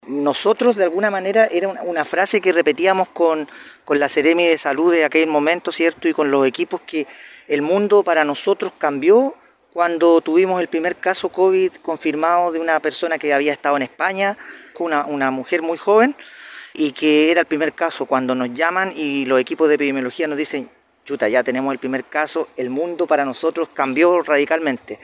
Por su parte el alcalde de Castro Juan Eduardo Vera, manifestaba así su preocupación por la amenaza de contagio   La Seremi de Salud de la región de Los Lagos de la época, Scarlet Molt recordó así ese primer contacto con la pandemia.